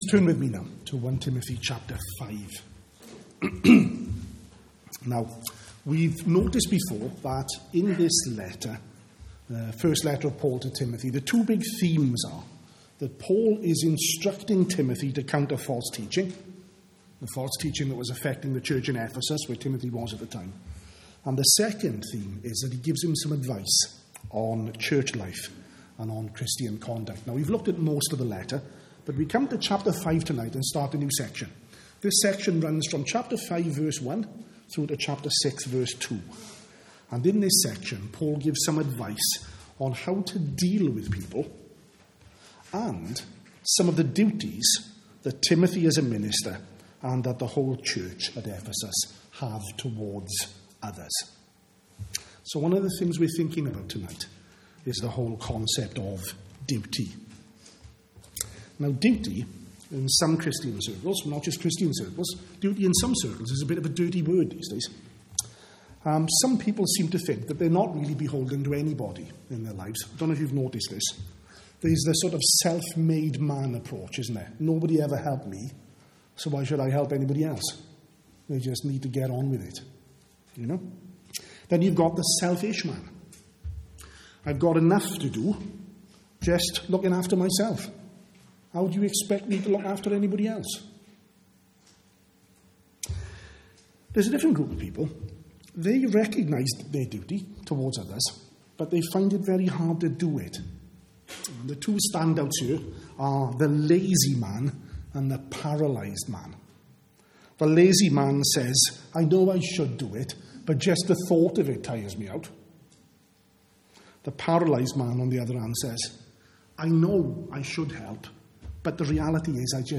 at the evening service